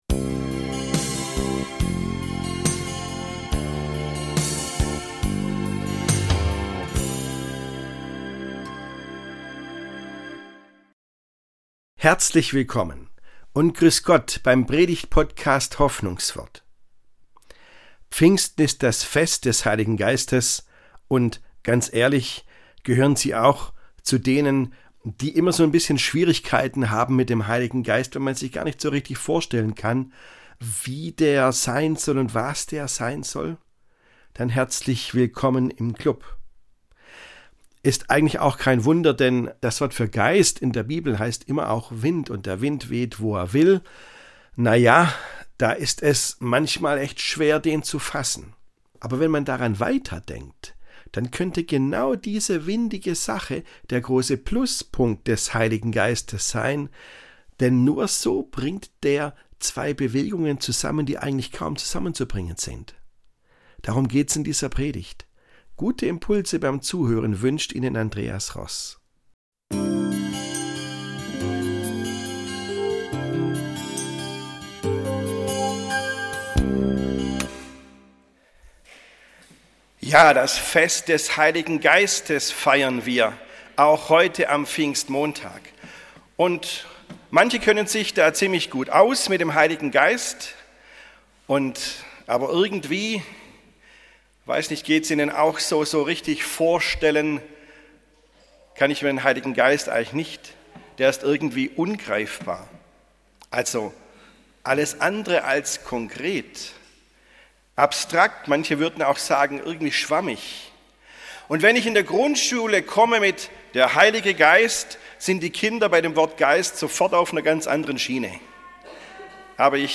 Pfingsten für windige Typen ~ Hoffnungswort - Predigten